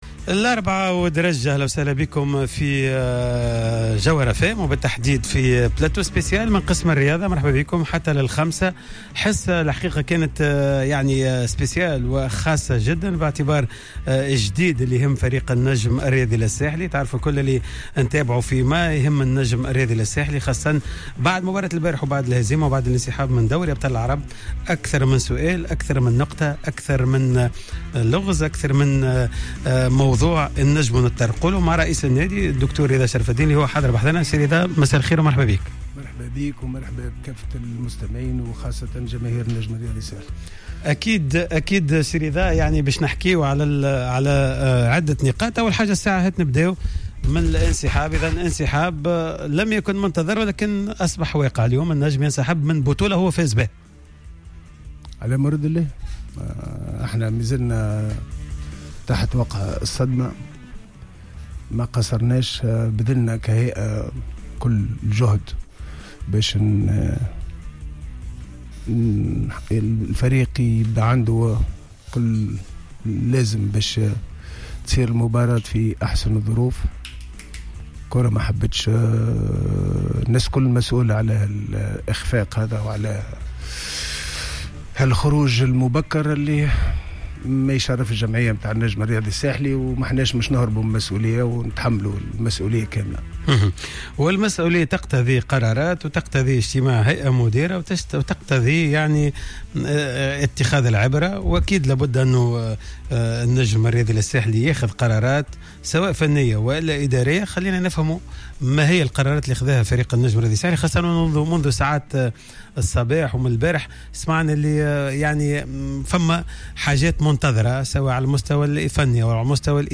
اعلن رئيس النجم الرياضي الساحلي خلال حواره الخاص اليوم على الجوهرة اف ام أن حضور جماهير النجم الساحلي في لقاء أشانتي كوتوكو الغاني المبرمج يوم الأحد 29 سبتمبر 2019 في ملعب مصطفى بن جنات قد أصبح صعبا بسبب أعمال الشغب التي طالت بعض الممتلكات الخاصة في محيط الملعب إثر نهاية المقابلات السابقة للفريق.